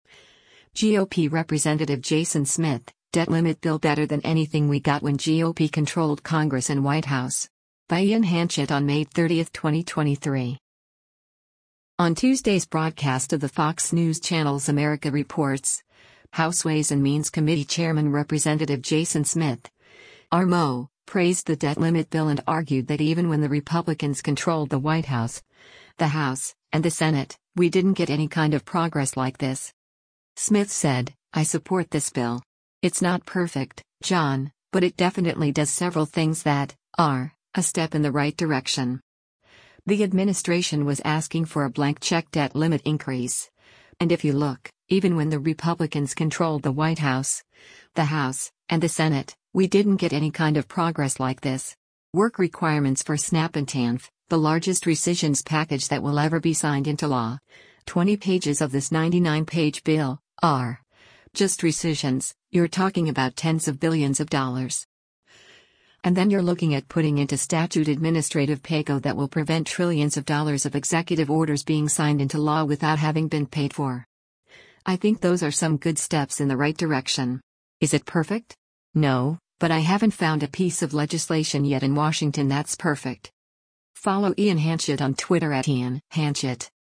On Tuesday’s broadcast of the Fox News Channel’s “America Reports,” House Ways and Means Committee Chairman Rep. Jason Smith (R-MO) praised the debt limit bill and argued that “even when the Republicans controlled the White House, the House, and the Senate, we didn’t get any kind of progress like this.”